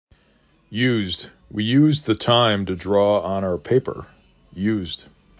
used 4 /y/ /u/ /z/ /d/ frequency: 1,096
'yu z d